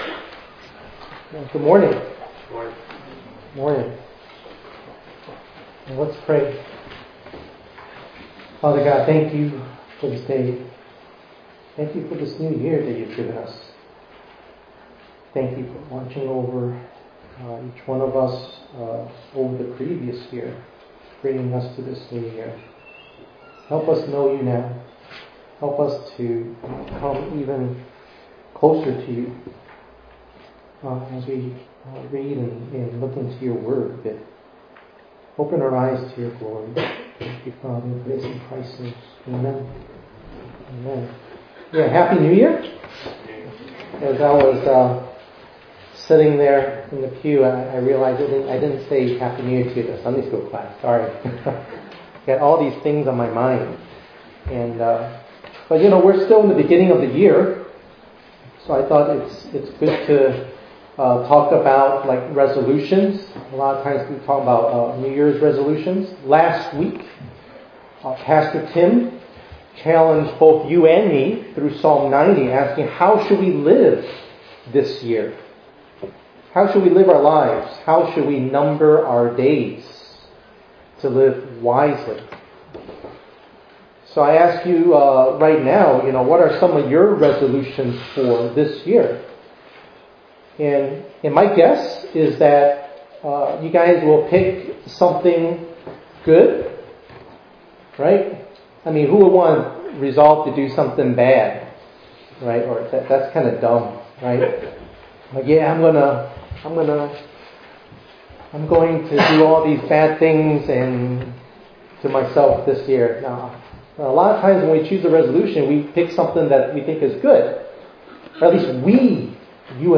1_11_26_ENG_Sermon.mp3